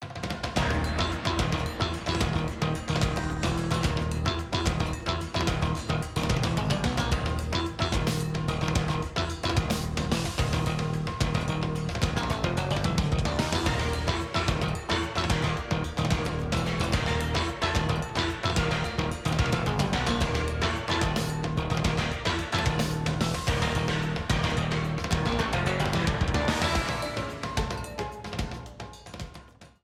A purple streamer theme
Ripped from the game
clipped to 30 seconds and applied fade-out
Fair use music sample